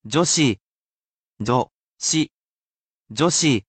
However, it is not only written, I will be here to pronounce it for you character by character.